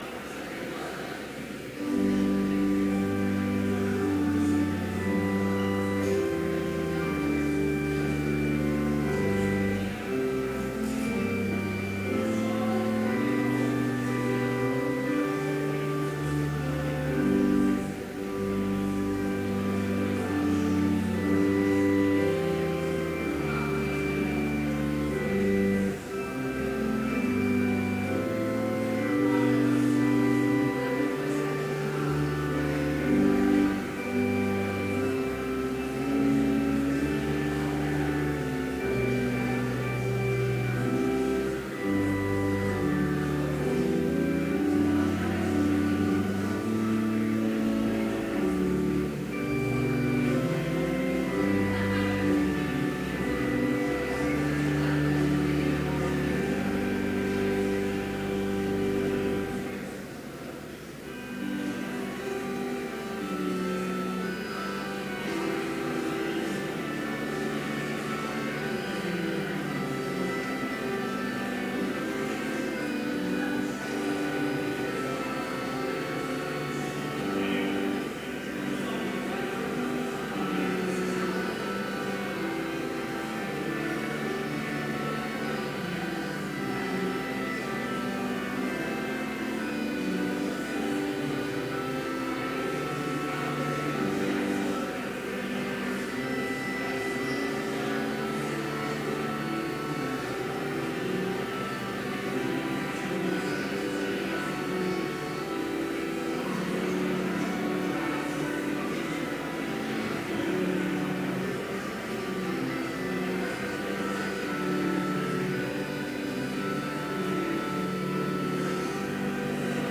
Complete service audio for Chapel - September 25, 2017